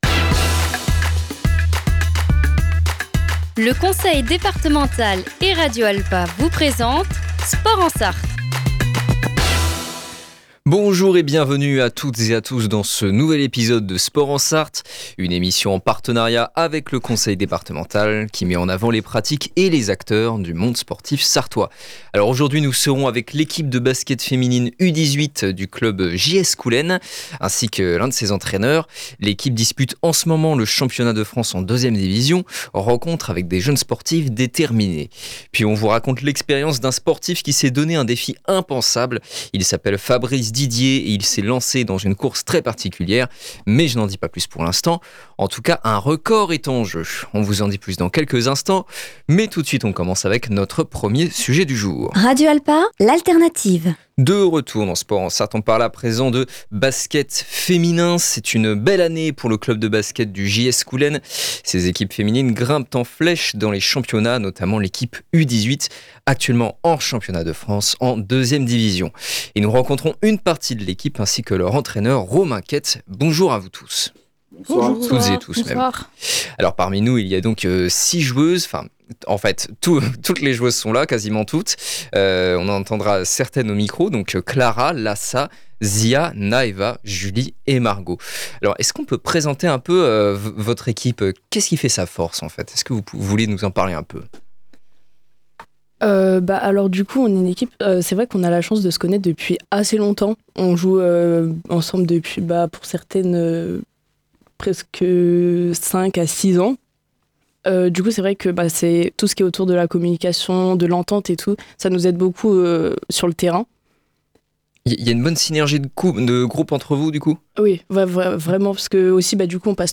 Aujourd’hui, nous serons avec l’équipe de basket féminine U18 du club JS Coulaines, ainsi que ses entraineurs ! L’équipe dispute en ce moment le championnat de France en 2e division. Rencontre avec des jeunes sportives déterminées !